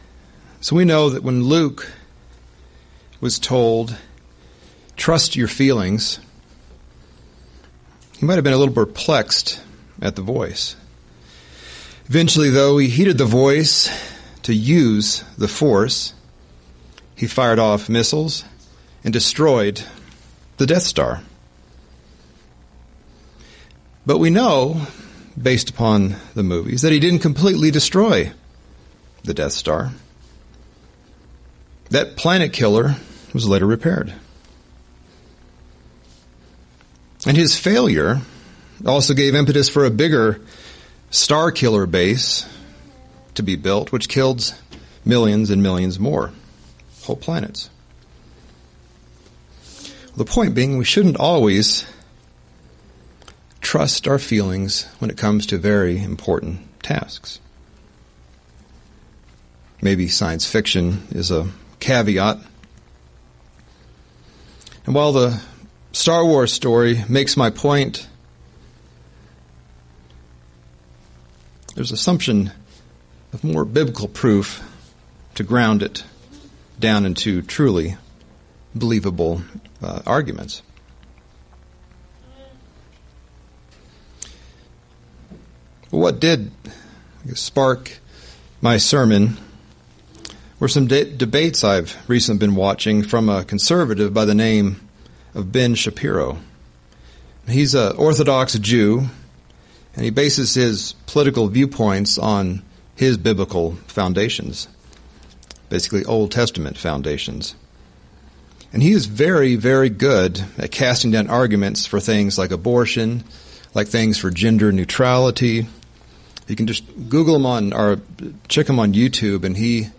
Sermons – Page 94 – Church of the Eternal God